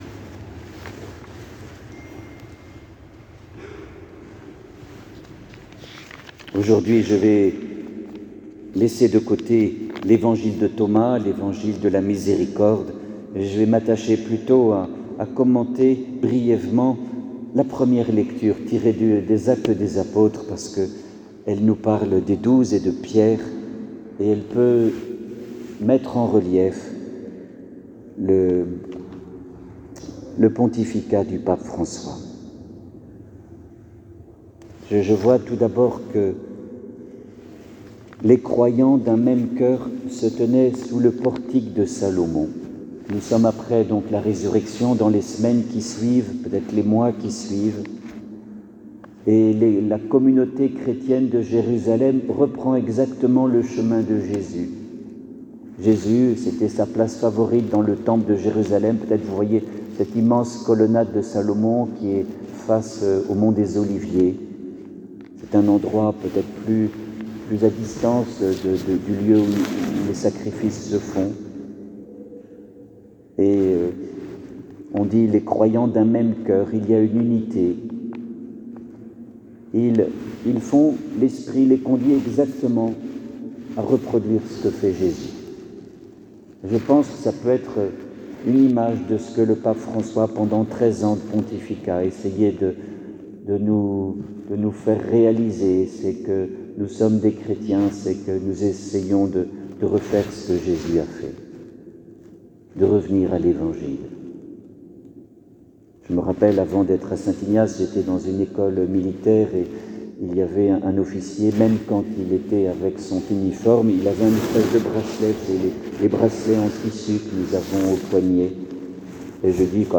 Homelie-2eme-dim-Paques.mp3